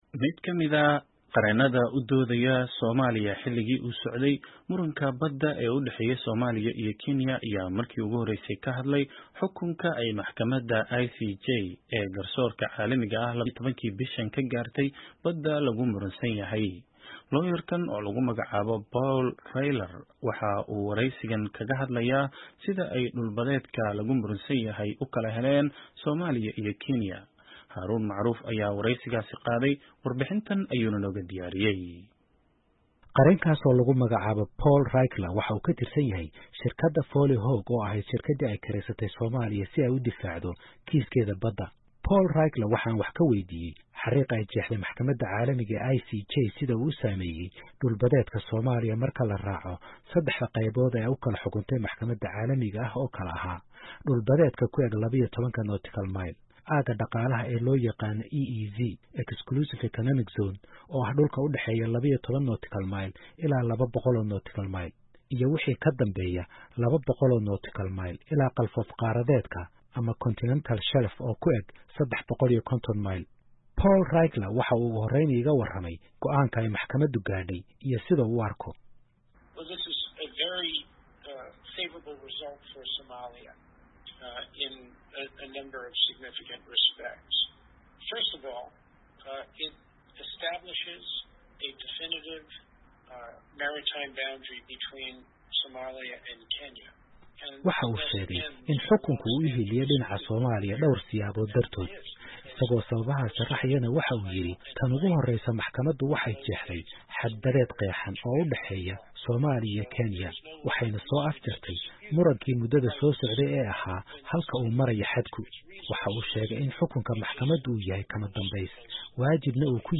Wareysi: Qareenka Soomaaliya oo sharxay cabirka dhul-badeedka ay heshay